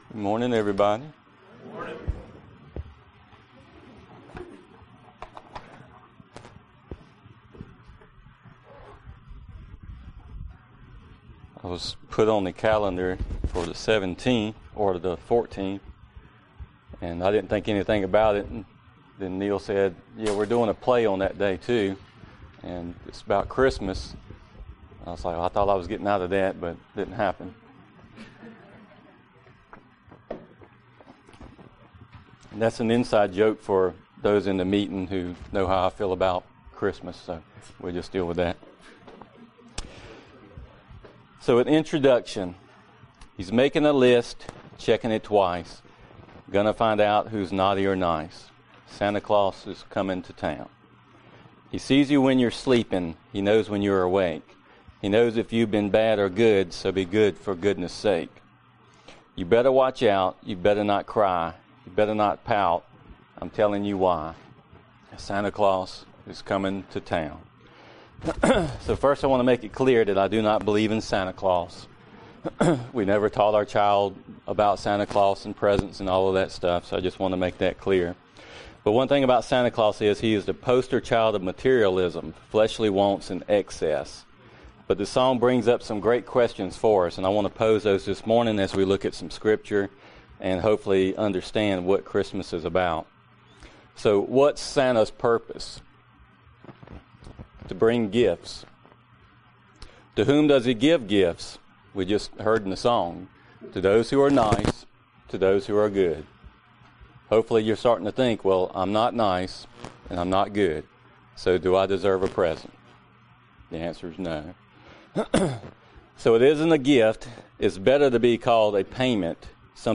Passage: John 3:16 Service Type: Sunday Morning Related « To Whom Little is Forgiven